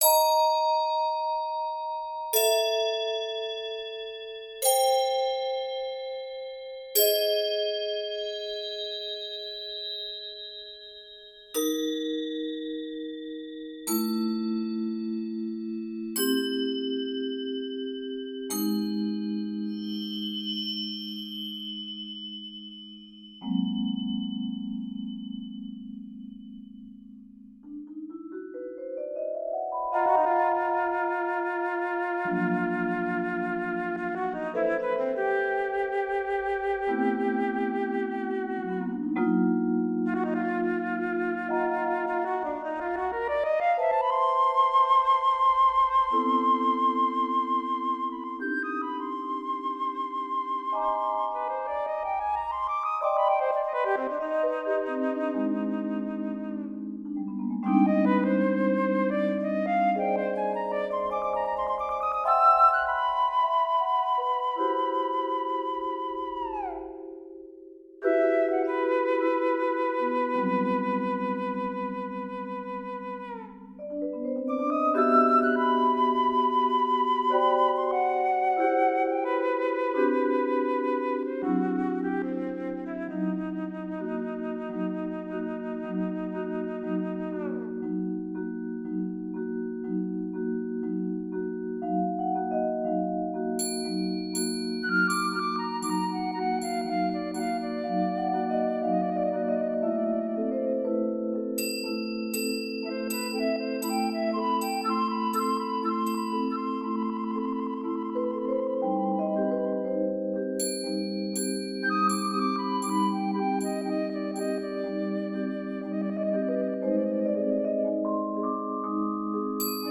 with the harsh, metallic sounds of crotales and vibraphone